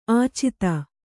♪ ācita